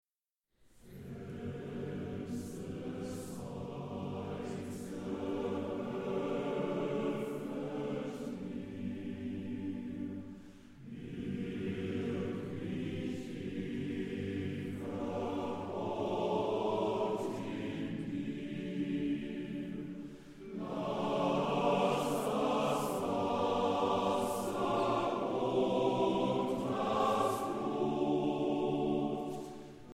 Festliches Konzert zu Ostern